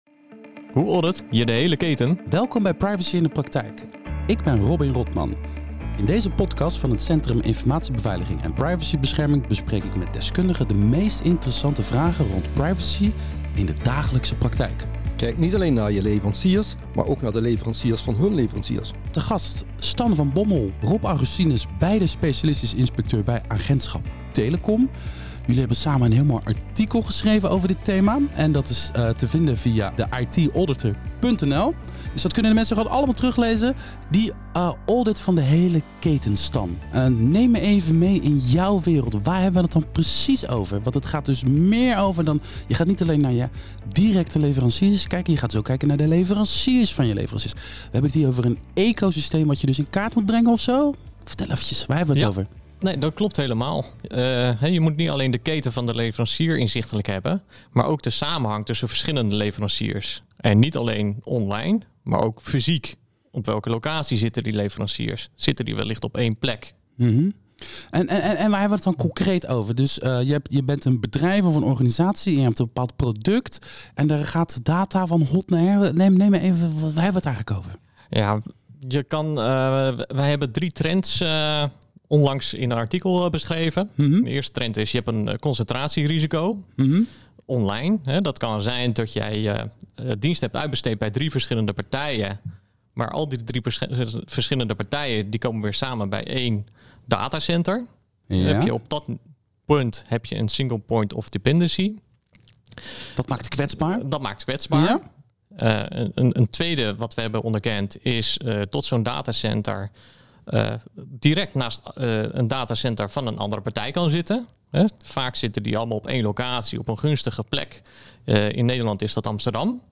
In podcasts van het Centrum Informatiebeveiliging en Privacybescherming bespreek ik met deskundigen de meest interessante vragen rond privacy in de dagelijkse praktijk.